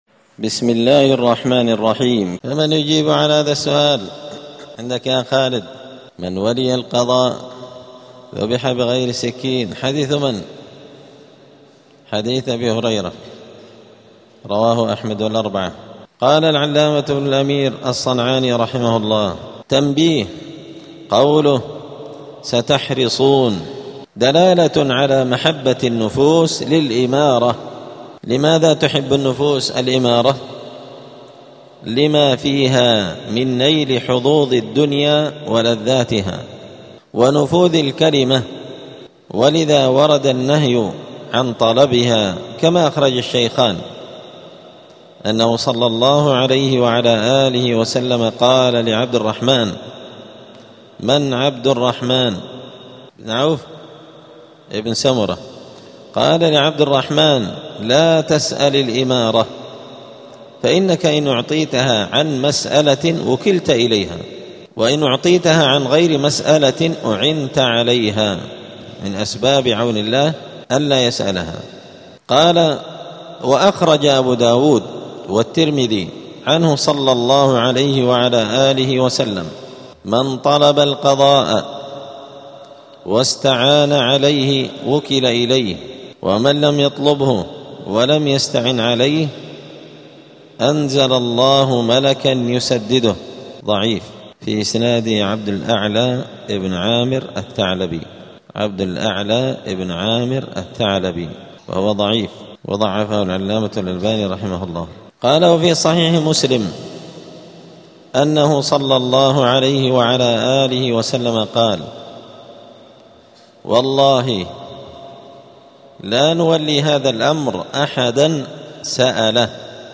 *الدرس الثالث (3) {ﺗﺒﻌﺎﺕ اﻟﻘﻀﺎء ﻭاﻹﻣﺎﺭﺓ}*
دار الحديث السلفية بمسجد الفرقان قشن المهرة اليمن